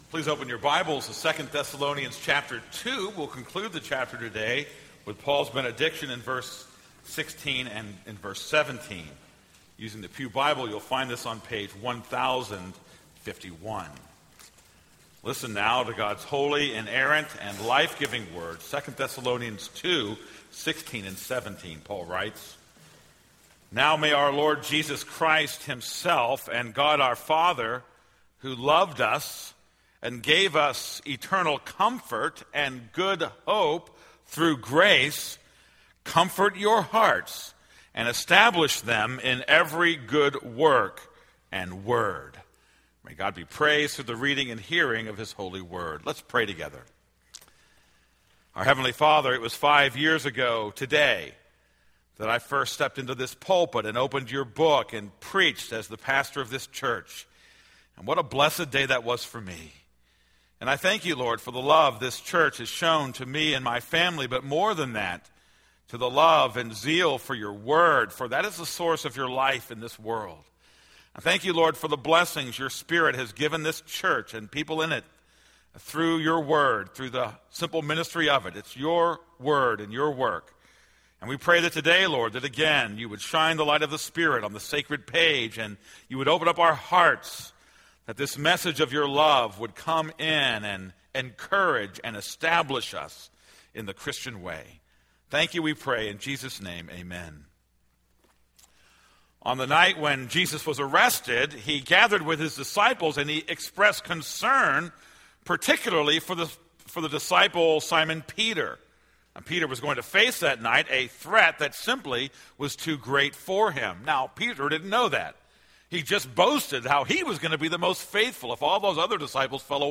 This is a sermon on 2 Thessalonians 2:16-17.